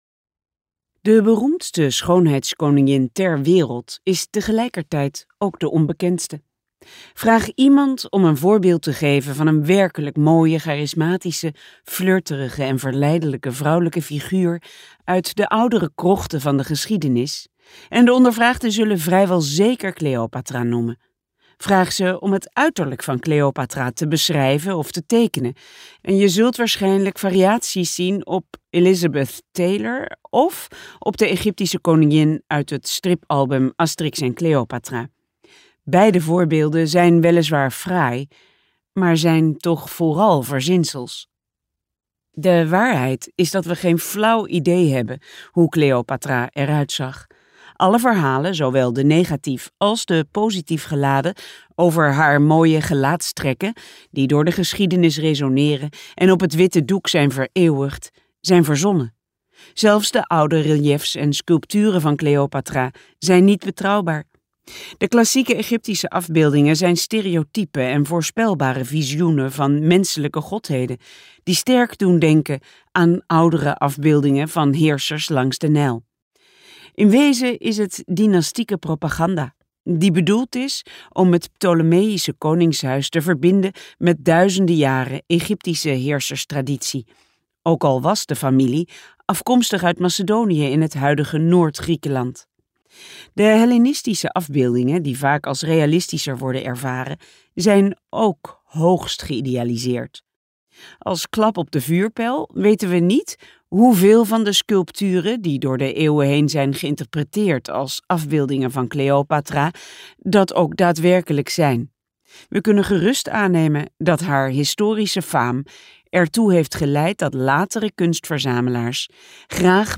Uitgeverij Omniboek | Cleopatra luisterboek